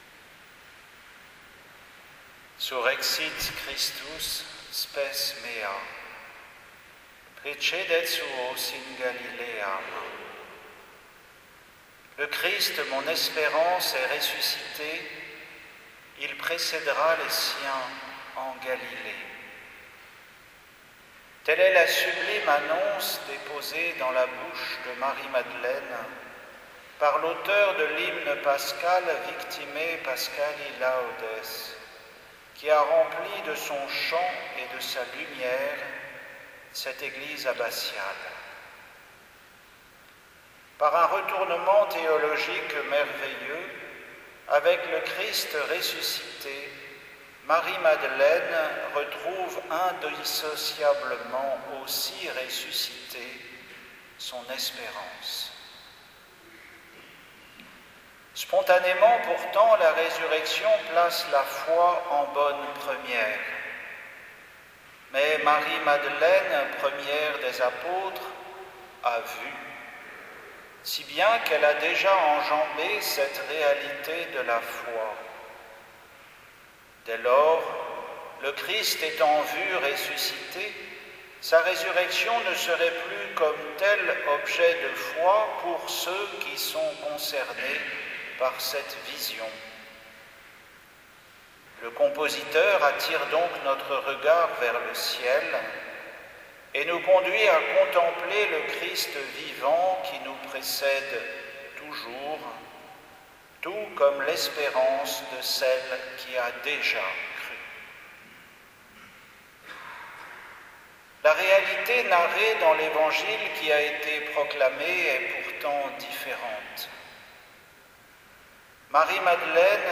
Homélie pour la solennité de Pâques 2023